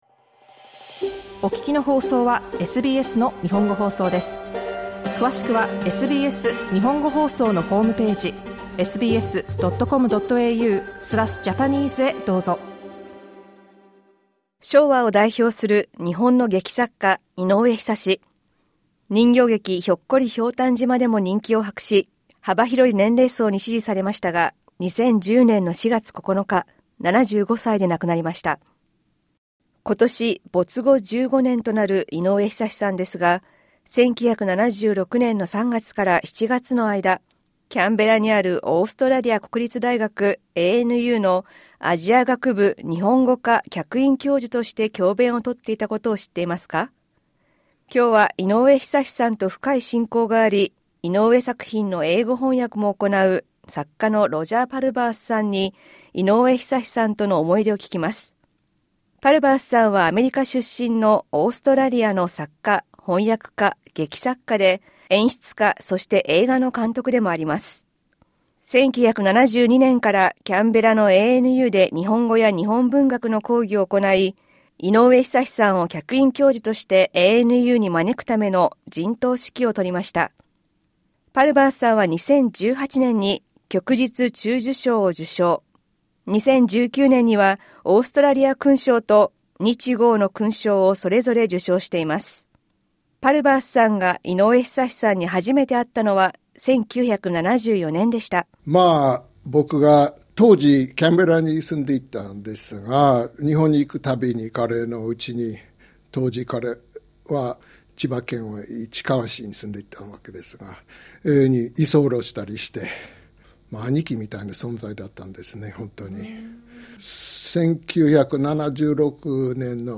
キャンベラのオーストラリア国立大学（ANU）で教鞭をとっていたことを知っていますか？没後15年の今年、井上ひさしさんと深い親交があり、作品の英語翻訳も行うオーストラリアの作家ロジャー・パルバースさんに思い出を聞きました。